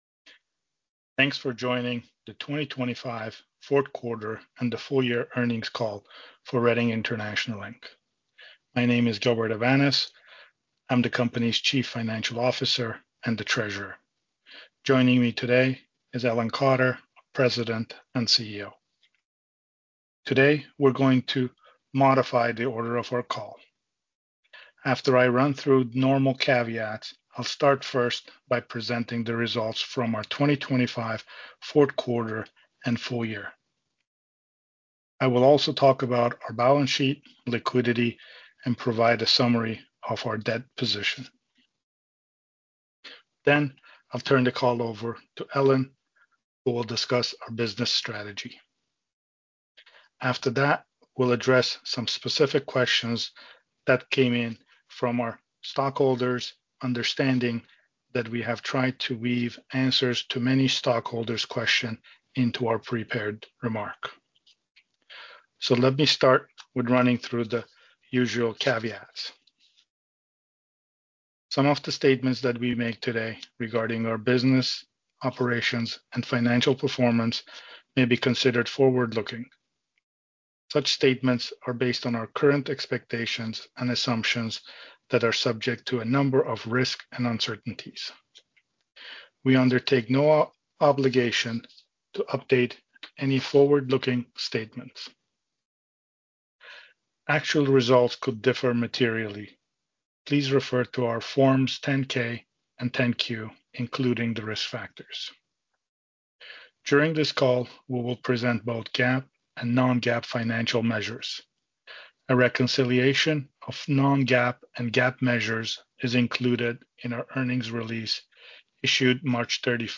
Question and answer session will follow the formal remarks.